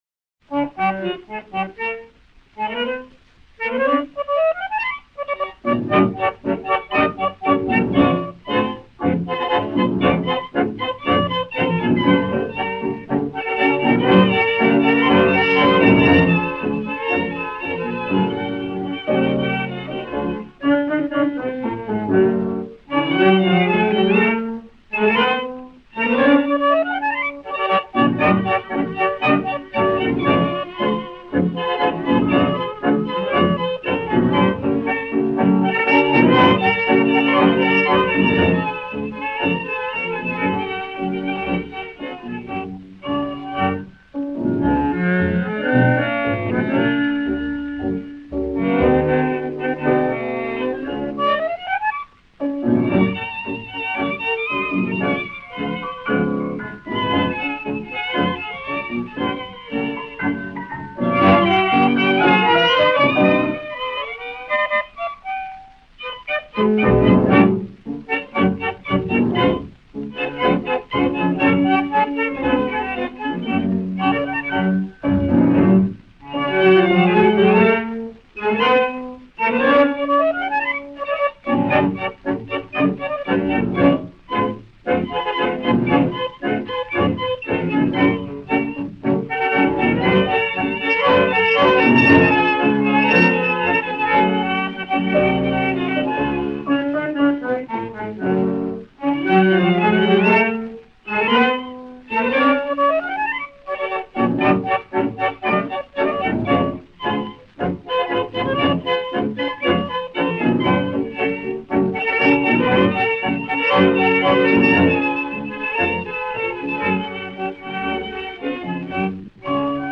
Танго
Instrumental